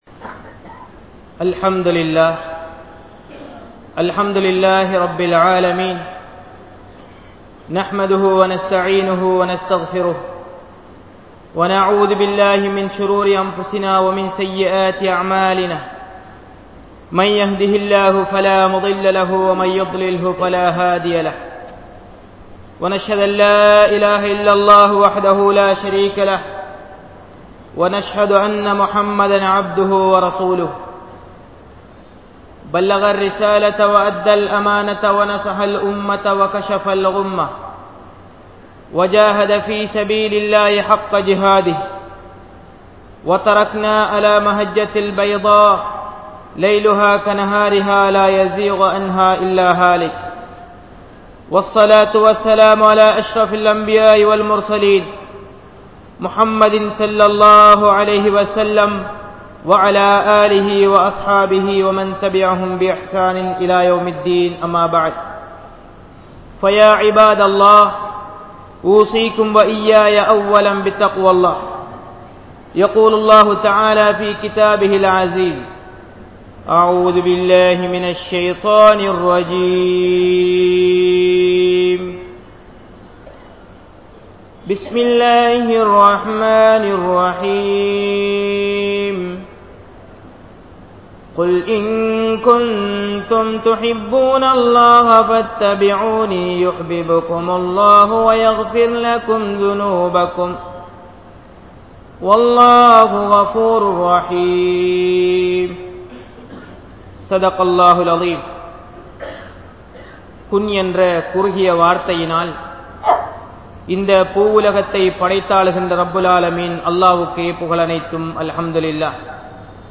Nabi(SAW)Avarhalai Neasiungal (நபி(ஸல்)அவர்களை நேசியுங்கள்) | Audio Bayans | All Ceylon Muslim Youth Community | Addalaichenai
Grand Jumua Masjith